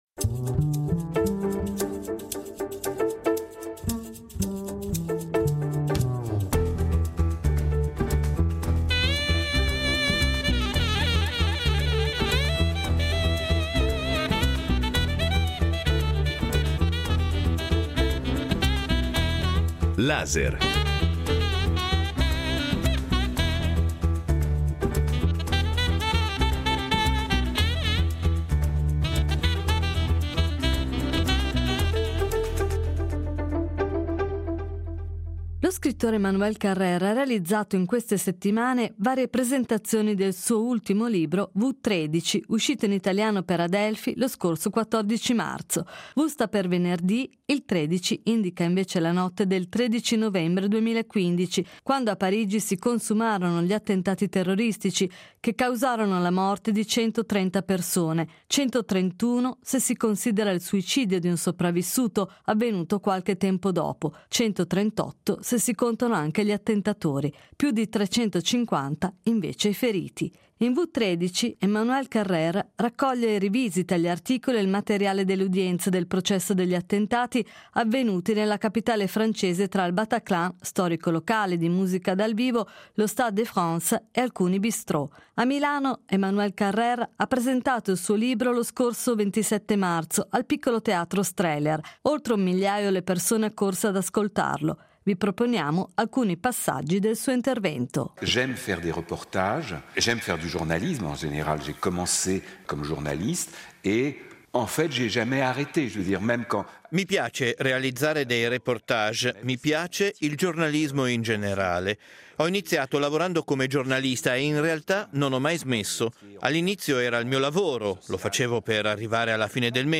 Laser ripropone una parte dell’incontro pubblico tenuto pochi giorni fa dallo scrittore francese al Piccolo Teatro Strehler di Milano.